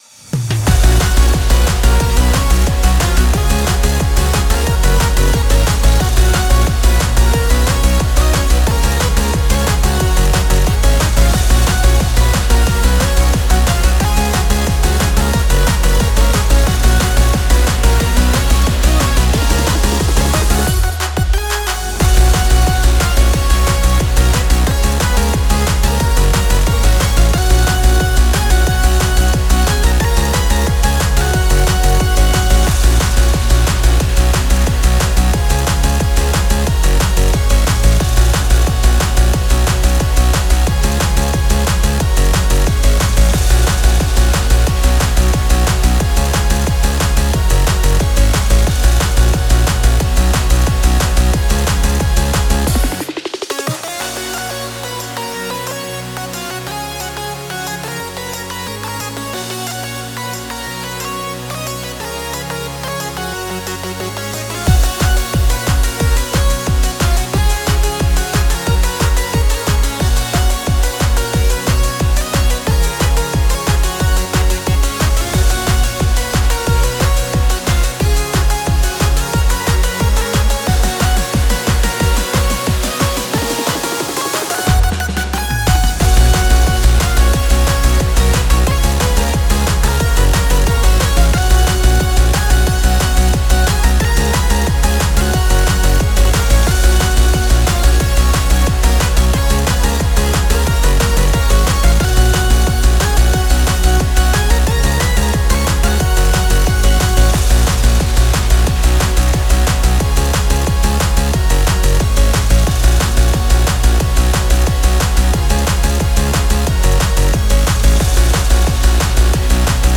かっこいい/明るい/EDM/オープニング
夏って感じのアップテンポで底抜けに明るい曲です。